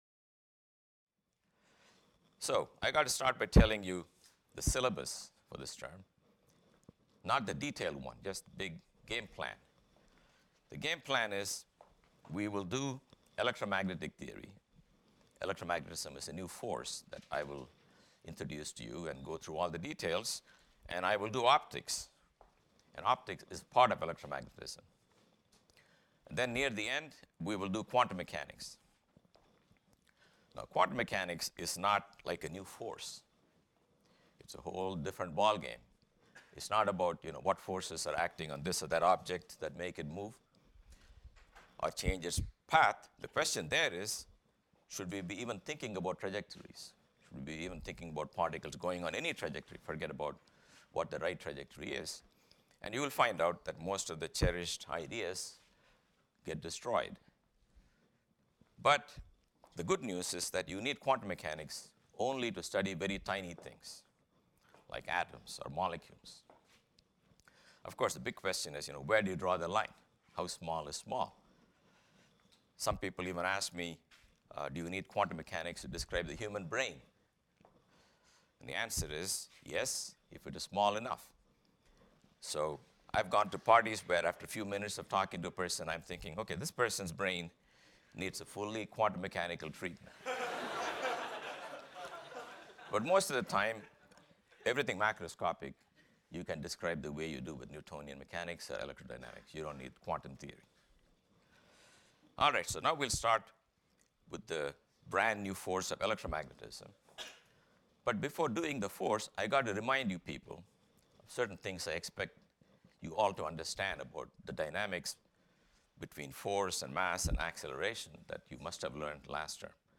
PHYS 201 - Lecture 1 - Electrostatics | Open Yale Courses